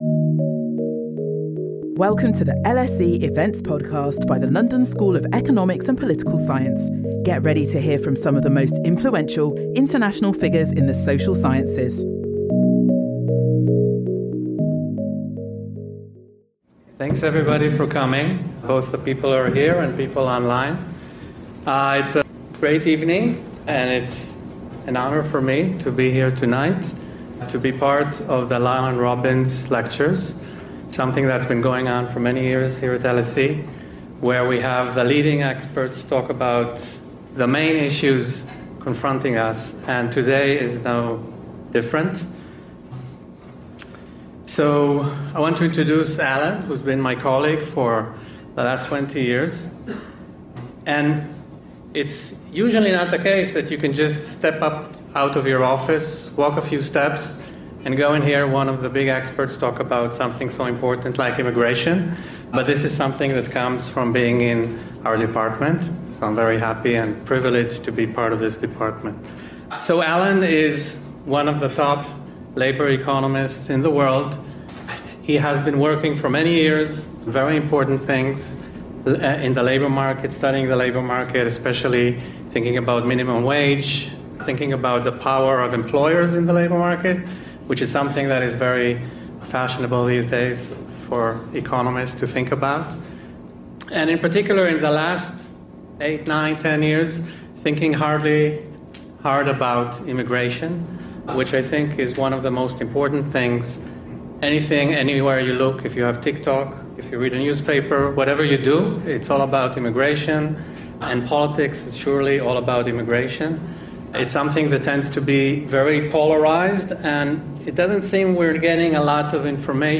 In this second lecture